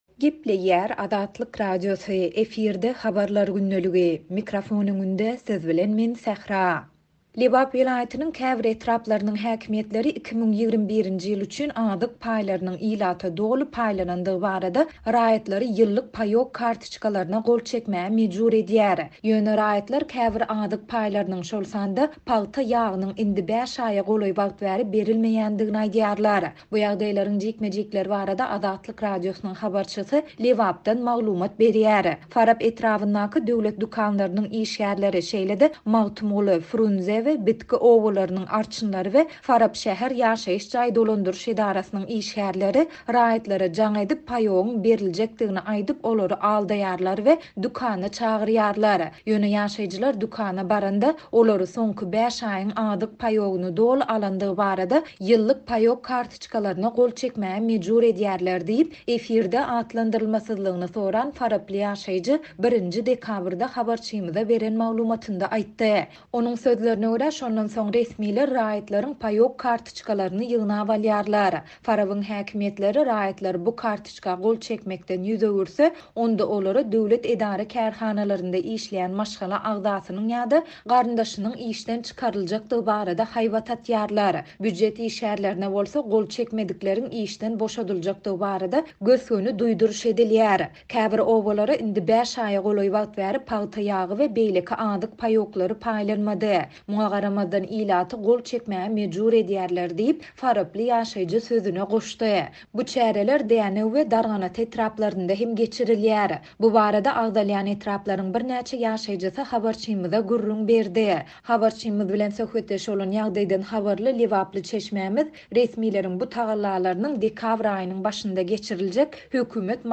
Bu ýagdaýlaryň jikme-jikleri barada Azatlyk Radiosynyň habarçysy Lebapdan maglumat berýär.